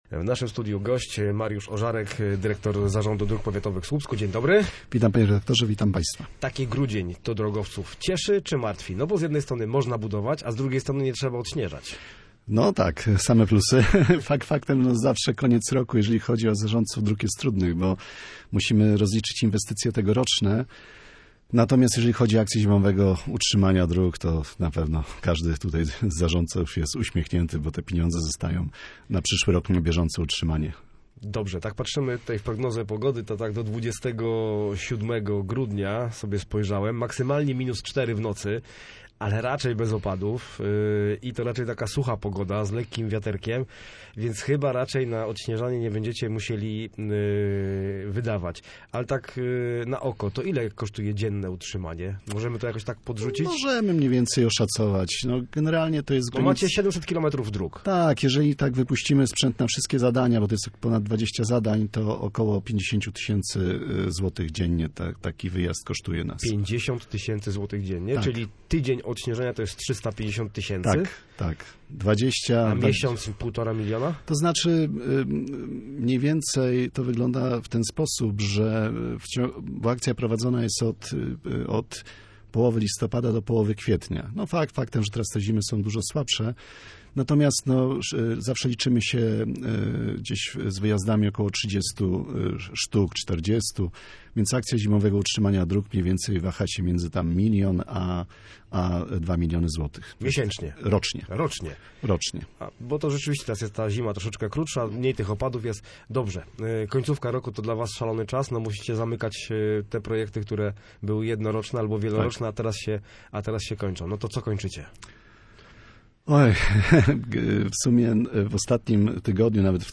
gościem miejskiego programu Radia Gdańsk Studio Słupsk 102 FM